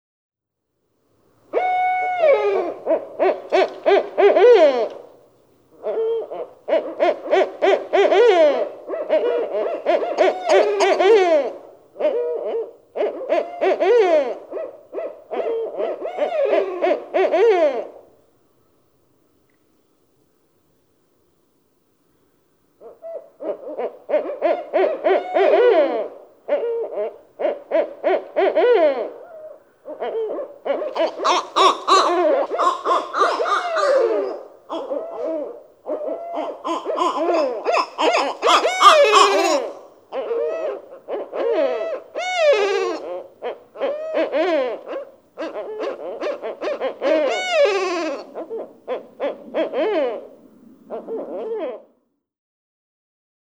Barred owl
Maniacal caterwauling of two barred owls.
Lawrence Swamp, Amherst, Massachusetts.
511_Barred_Owl.mp3